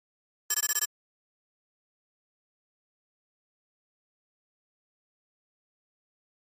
Flash Readout High Frequency Electronic Tapping